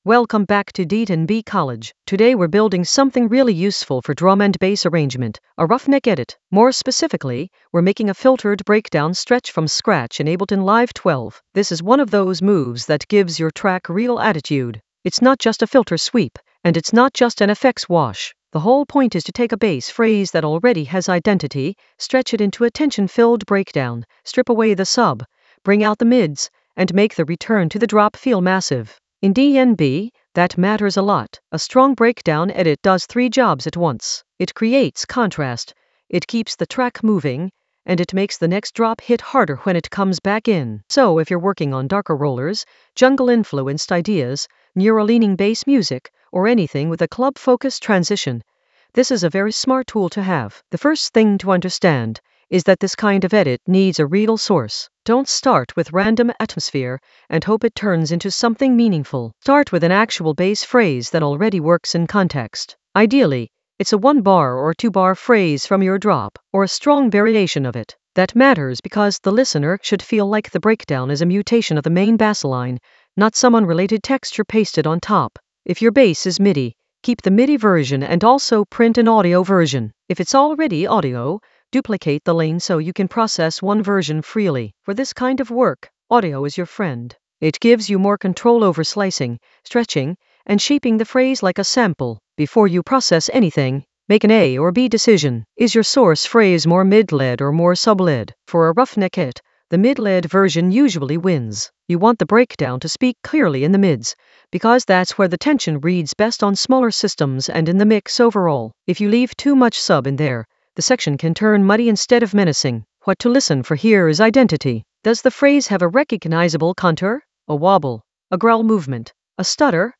An AI-generated intermediate Ableton lesson focused on Ruffneck edit: a filtered breakdown stretch from scratch in Ableton Live 12 in the Basslines area of drum and bass production.
Narrated lesson audio
The voice track includes the tutorial plus extra teacher commentary.